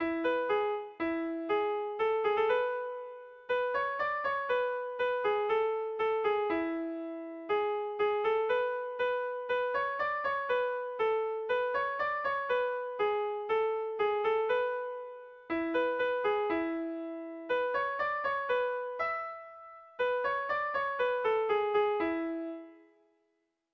Kontakizunezkoa
ABDE